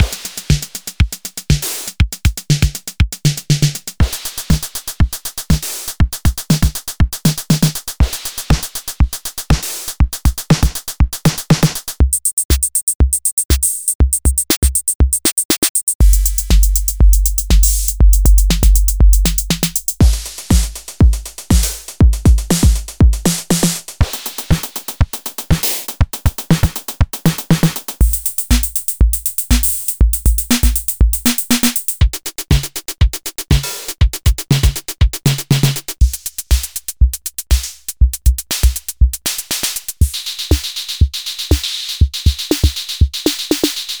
Звук с релиз-лупами вполне правдоподобный.